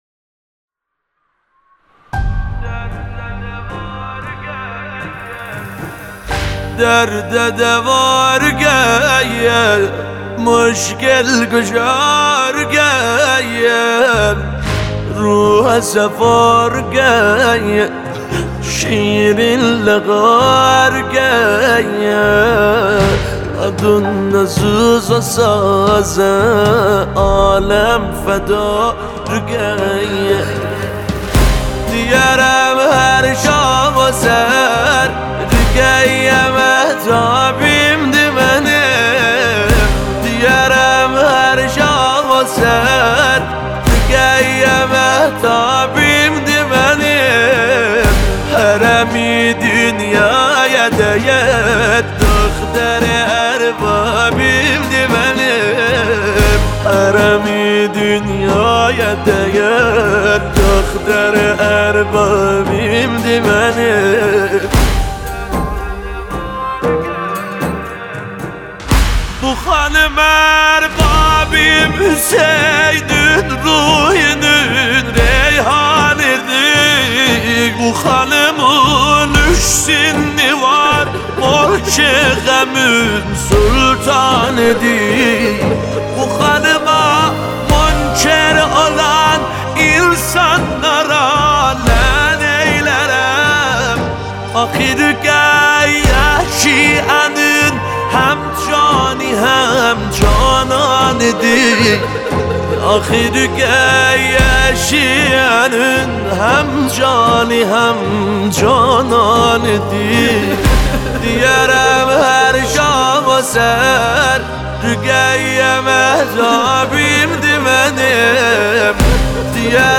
نوحه ترکی